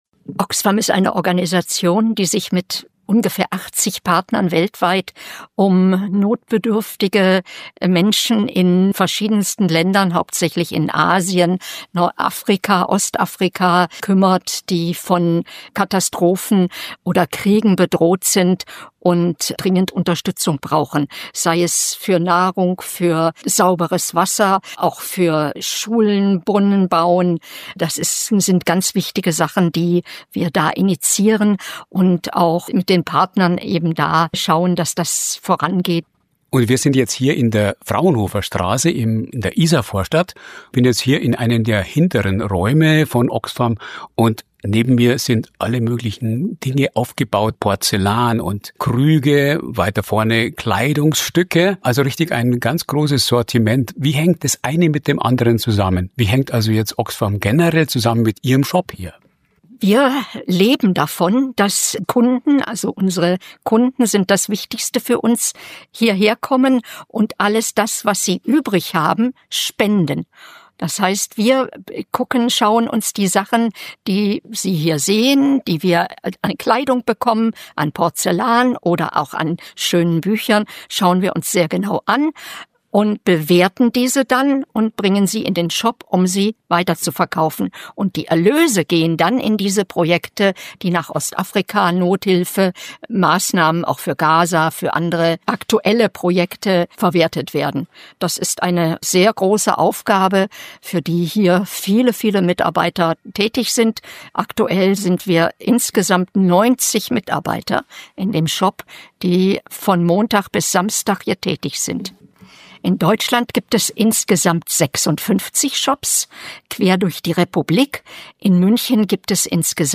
Heute ist der Internationale Tag des Ehrenamts – ein passender Anlass, den Oxfam-Shop in der Münchner Isarvorstadt zu besuchen. Wobei „Shop“ fast zu bescheiden klingt: Das kleine Kaufhaus wird von rund 90 Freiwilligen betrieben, die eine bunte Vielfalt an gespendeten Waren anbieten – alles für den guten Zweck.